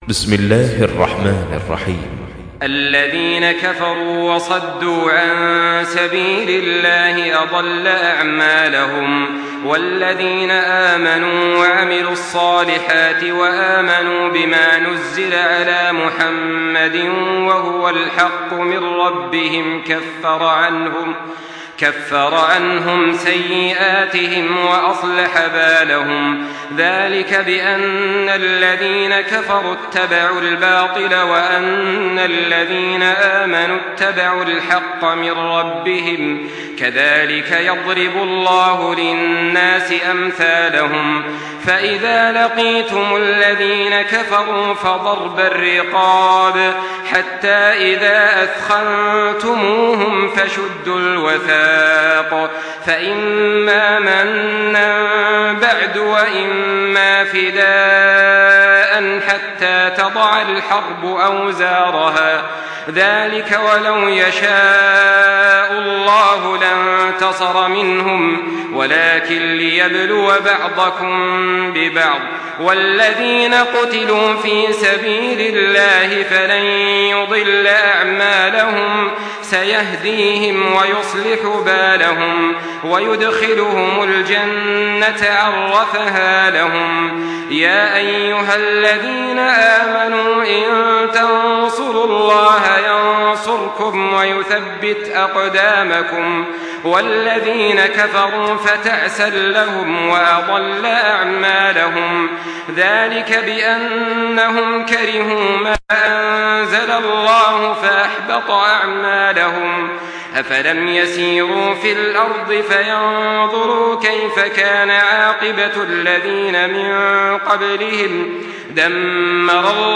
Makkah Taraweeh 1424
Murattal